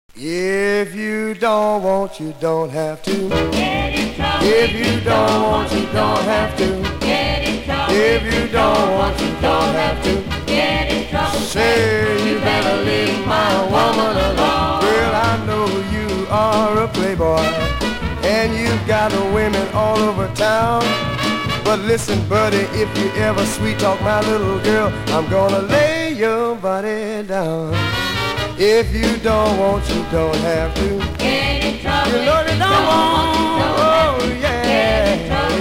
(税込￥2750)   SOUL, R&B